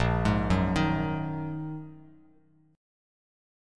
Bb7sus4#5 chord